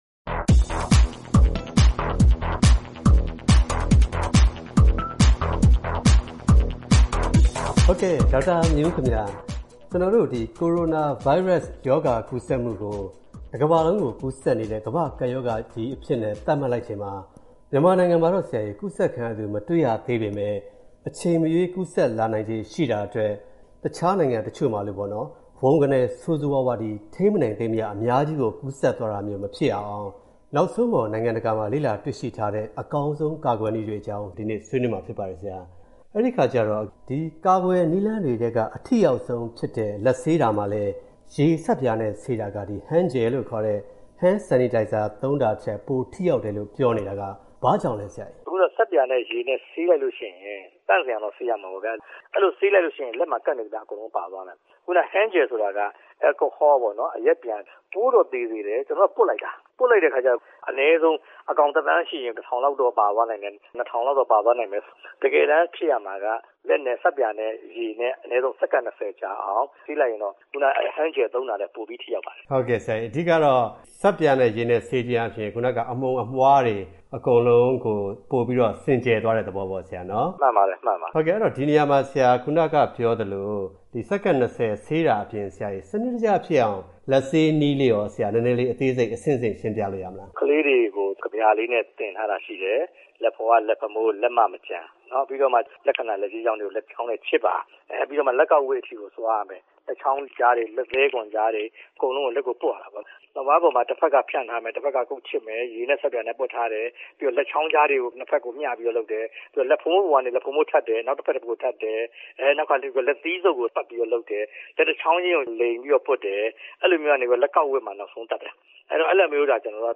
ဆက်သွယ်မေးမြန်း တင်ပြထားပါတယ်။